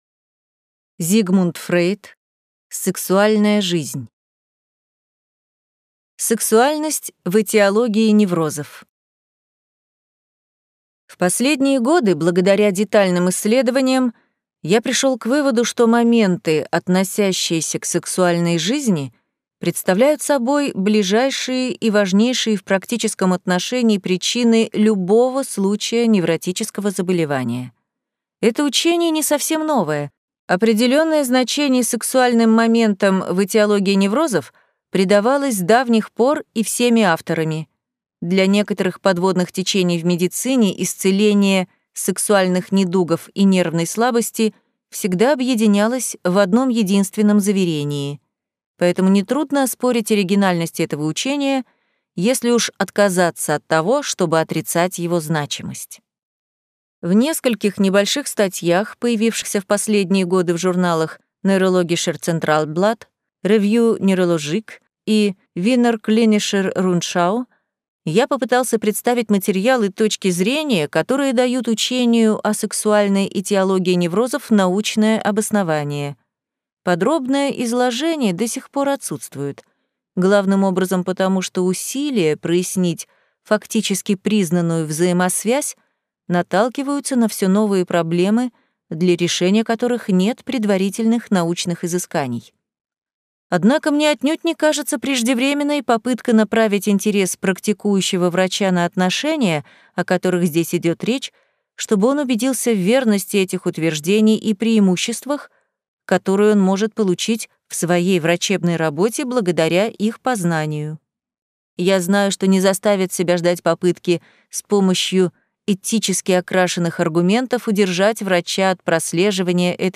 Аудиокнига Сексуальная жизнь | Библиотека аудиокниг